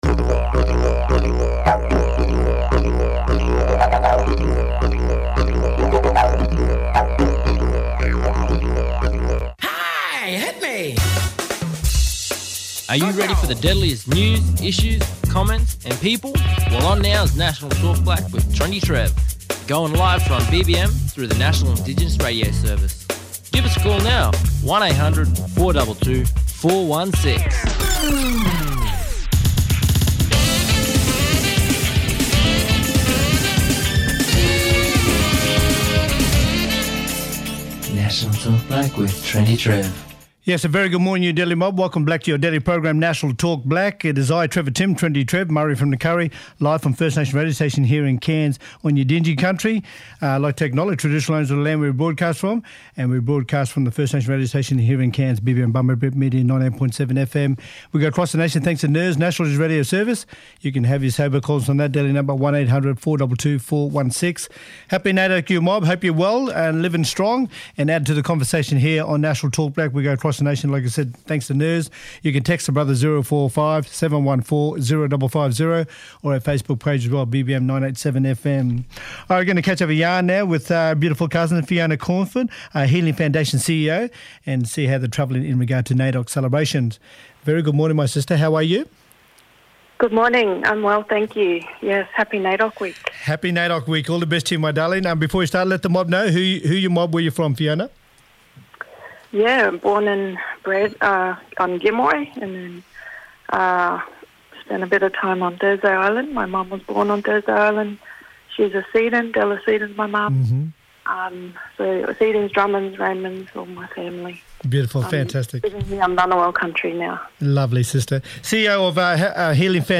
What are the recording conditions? in the studio to talk about NAIDOC week.